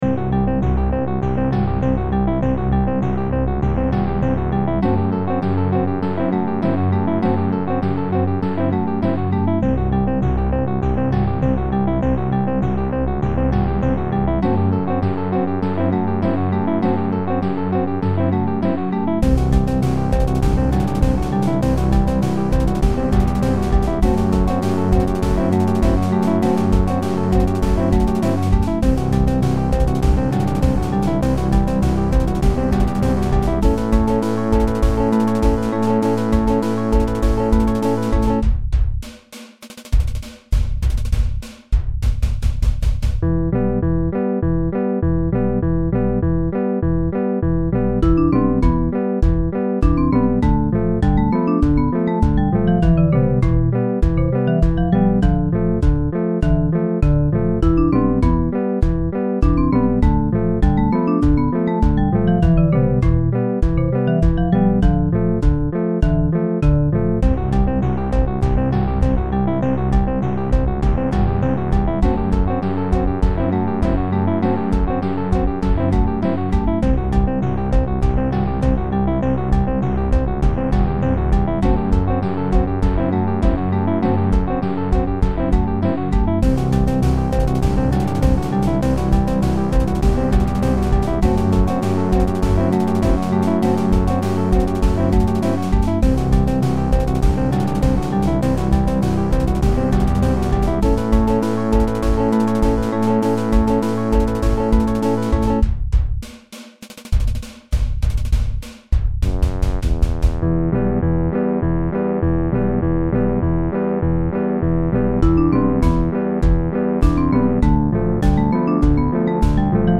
C Lydian 4/4 100bpm
chiptune